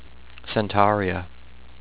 Pronunciation:
cen-TAR-ee-uh